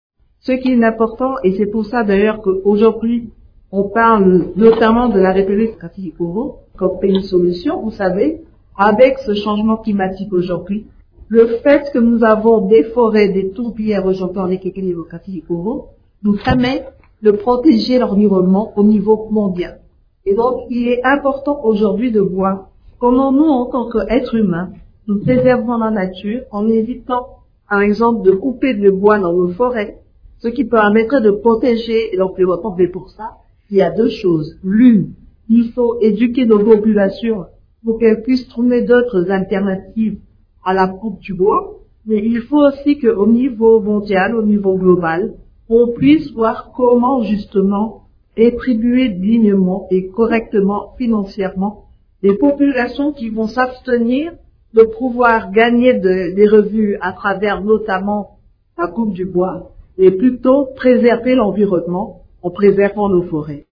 Intervenant vendredi 27 juin devant les étudiants et le personnel académique de l’université d’Osaka au Japon, la Première ministre congolaise, Judith Suminwa, a expliqué tous les efforts que son gouvernement fournis sous l’impulsion du président Felix Tshisekedi pour faciliter l’autonomisation des femmes et l’entreprenariat des jeunes en RDC.
Ecoutez par ailleurs la réponse de Judith Suminwa à la question d’un étudiant japonais sur les efforts fournis par le gouvernement congolais dans le domaine de l’environnement :